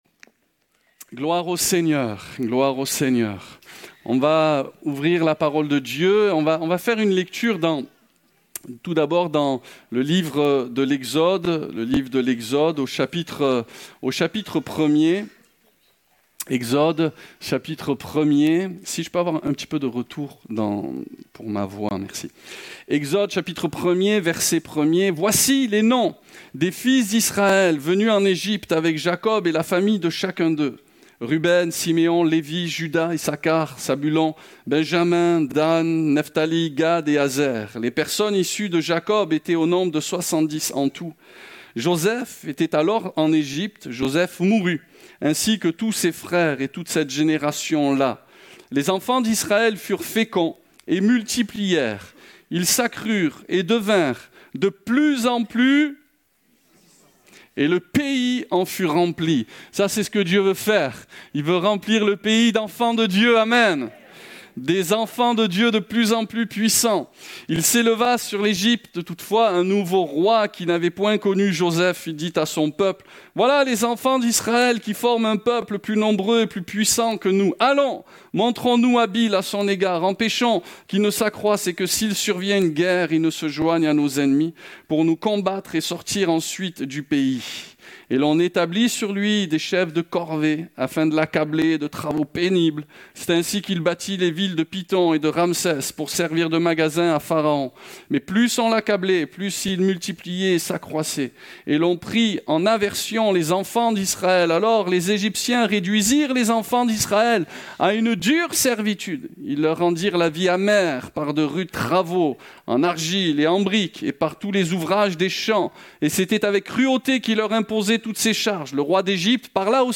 Découvrez en replay vidéo le message apporté à l'Eglise Ciel Ouvert
Réunion: Culte